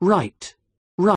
The RP PRICE diphthong [aɪ] also began further “clockwise” in the vowel space than it does today, as parodied in right royal knees-up:
The contemporary diphthong begins further back, with a quality better transcribed as [ɑ]. Compare the ad’s right royal with right from Macmillan online: